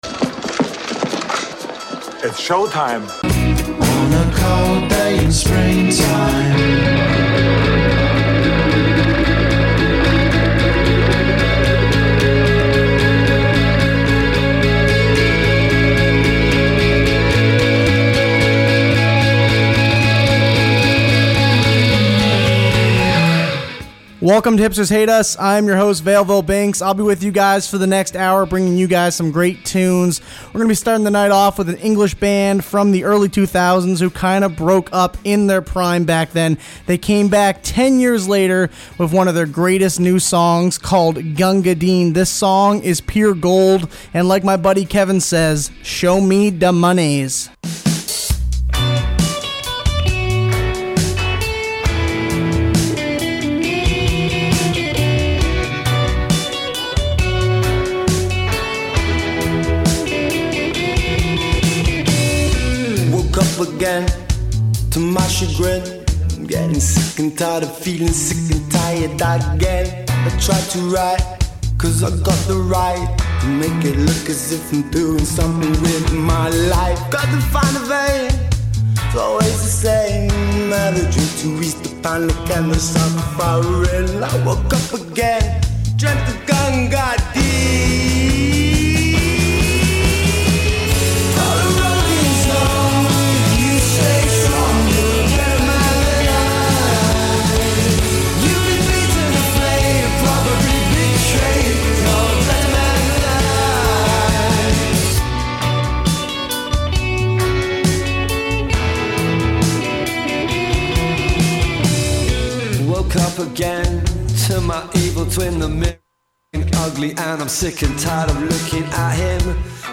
An Open Format Music Show. Britpop, Electronica, Hip-Hop, Alternative Rock, and Canadian music